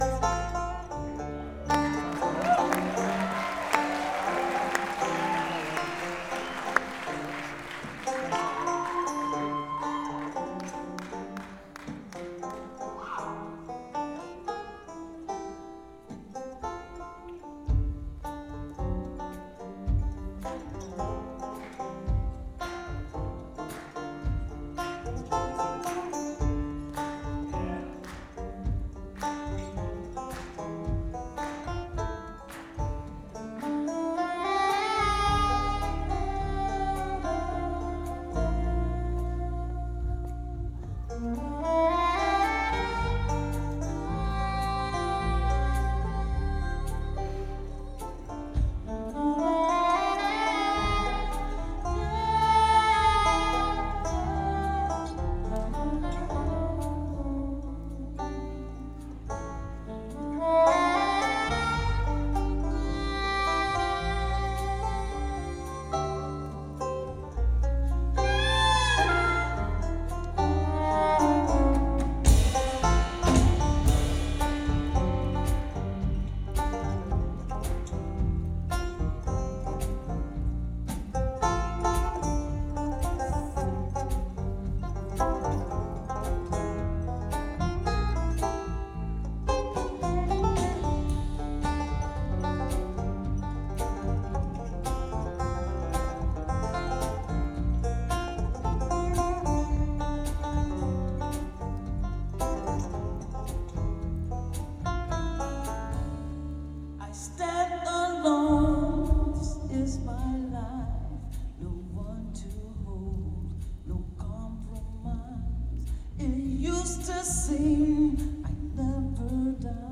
March 15, 2006 – Kentucky Theater, Lexington, KY